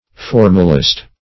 Formalist \Form"al*ist\, n. [Cf. F. formaliste.]